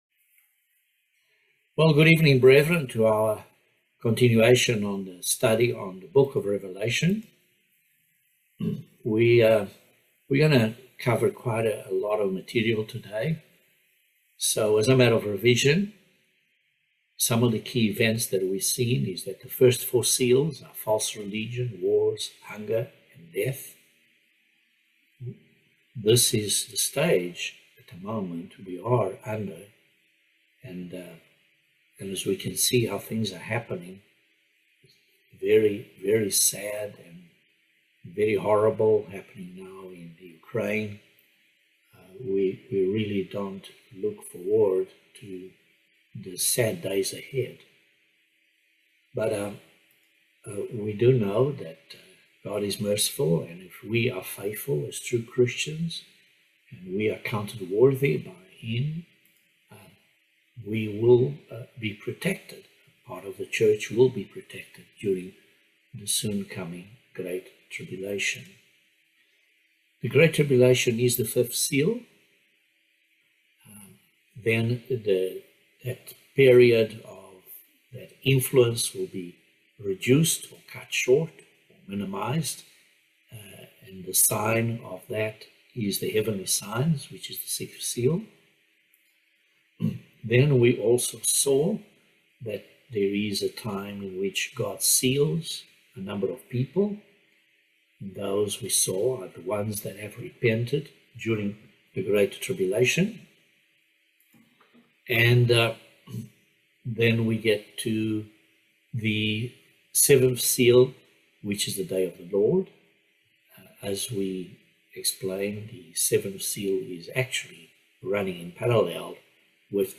Bible Study No 19 of Revelation